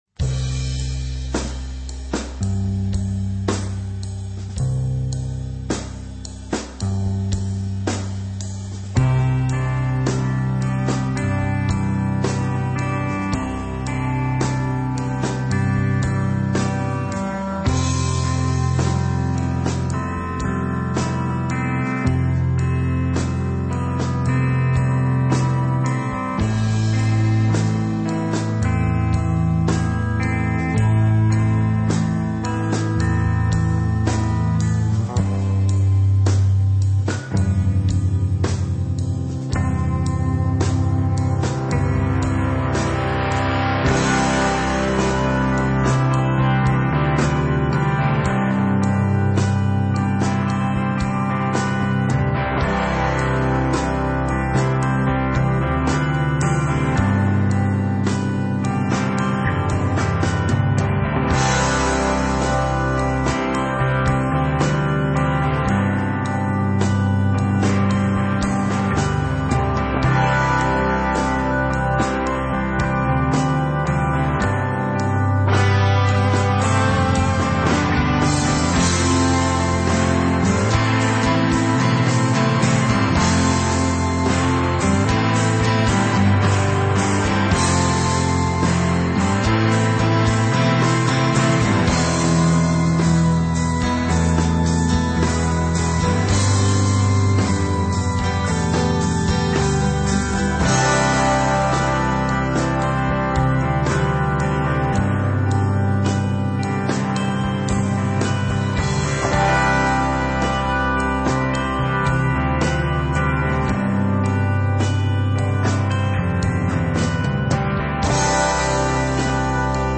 rock
metal
punk
high energy rock and roll